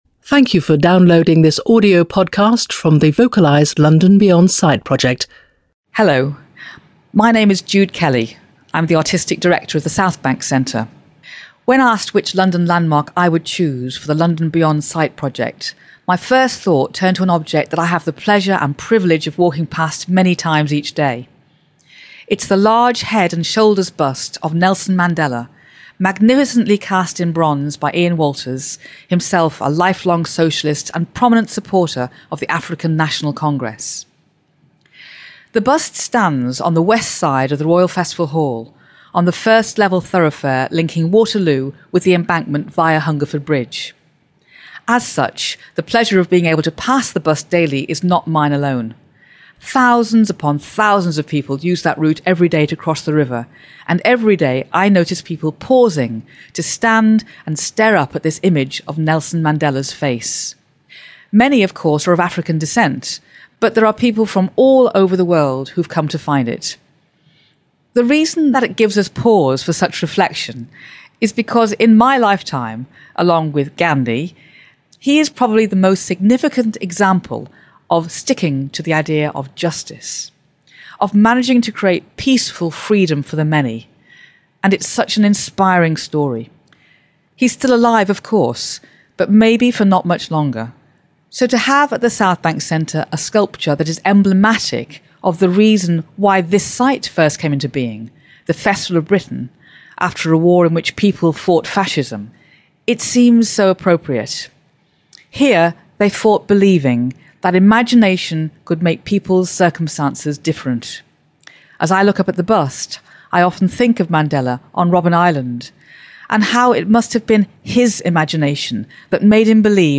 주드 켈리가 사우스뱅크 센터에 있는 넬슨 만델라 흉상을 설명하는 오디오